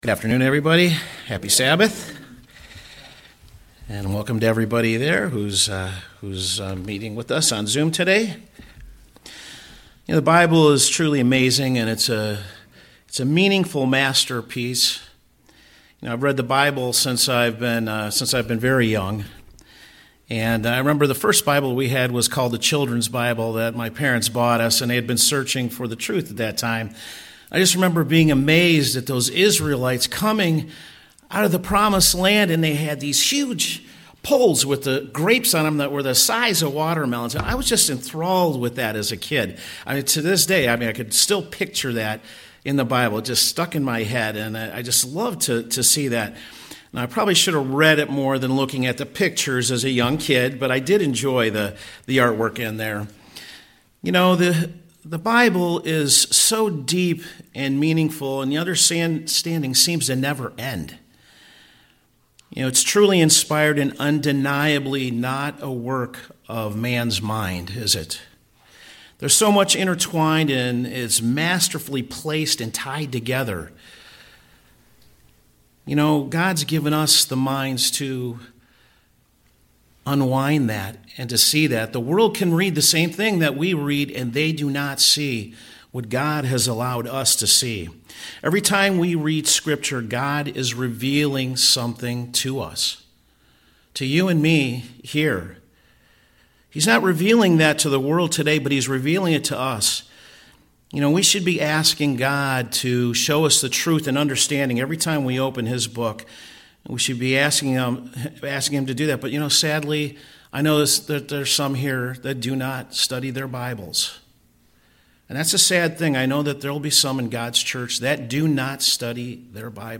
Sermons
Given in Cleveland, OH